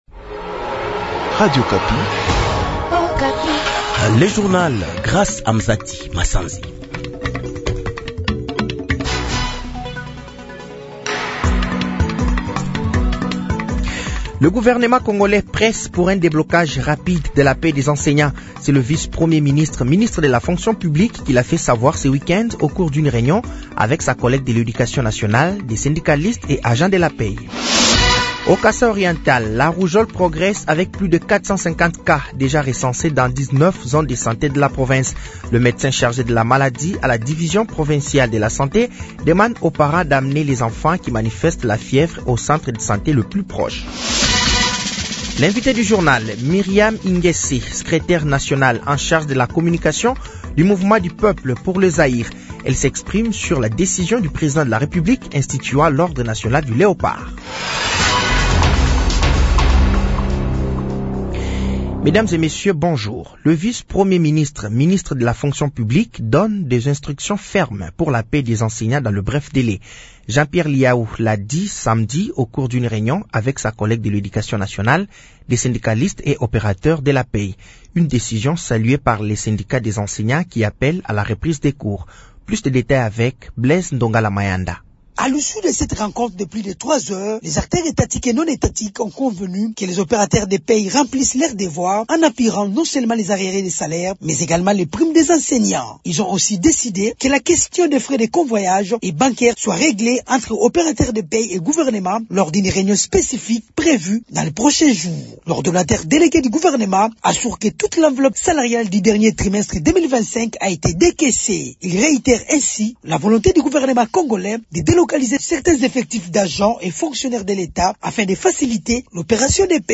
Journal français de 07h de ce lundi 05 janvier 2026